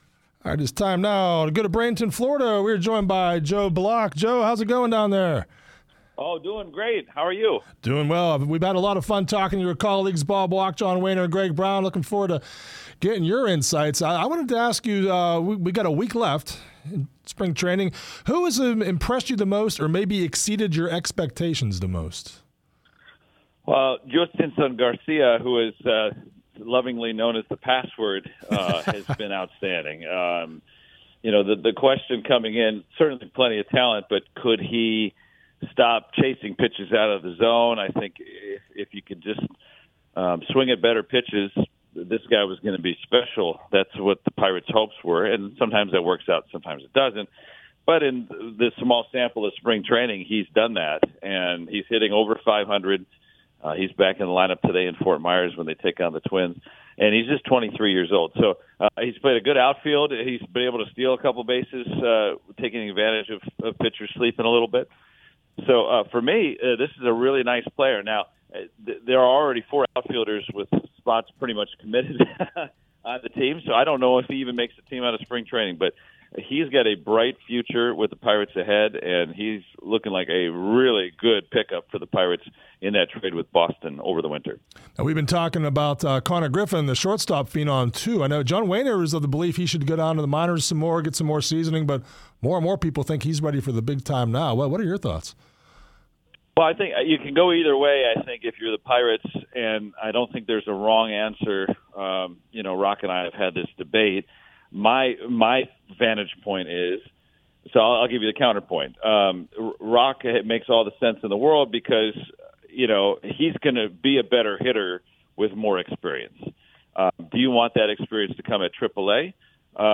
Here’s the interview: